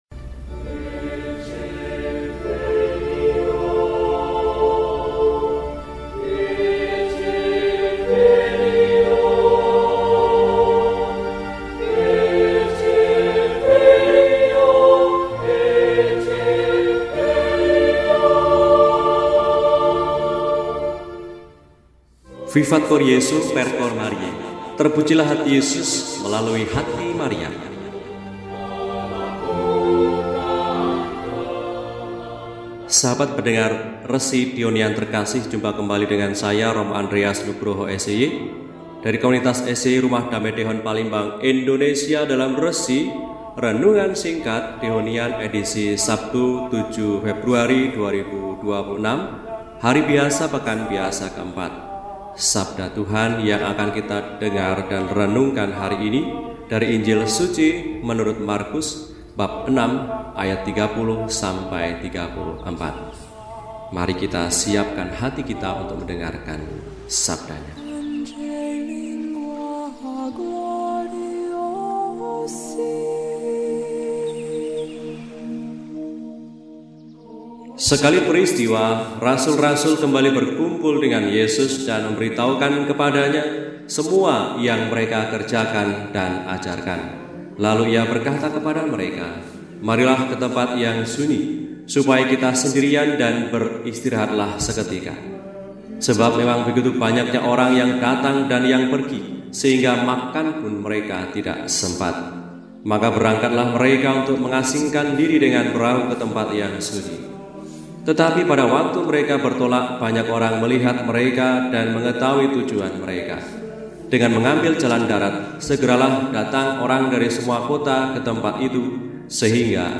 Sabtu, 07 Februari 2026 – Hari Biasa Pekan IV – RESI (Renungan Singkat) DEHONIAN